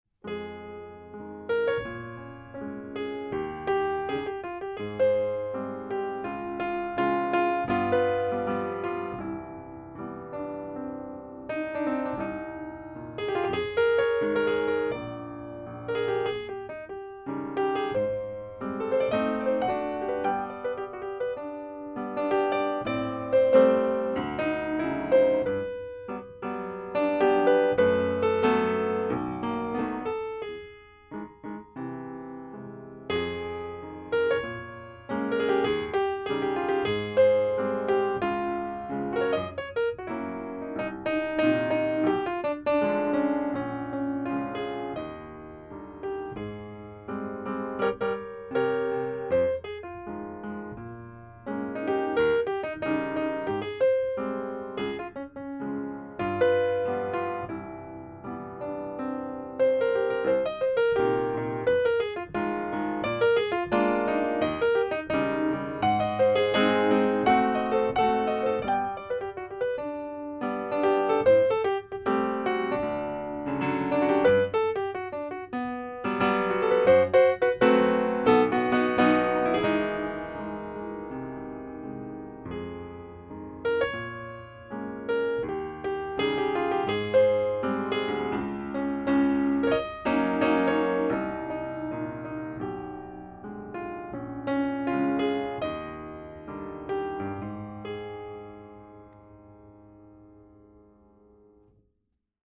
Studio upright in Yamaha's best selling U-series.